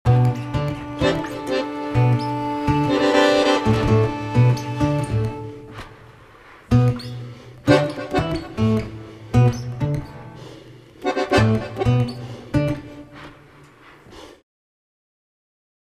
acc. git
accordion
cello